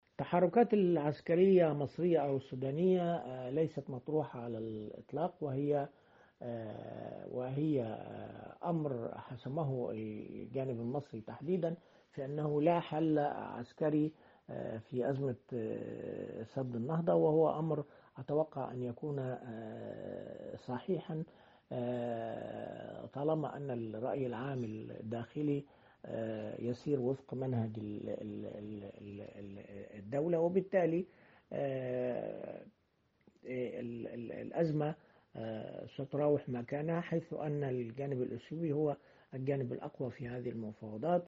كاتب صحفي ومحلل سياسي